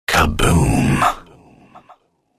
kaBOOM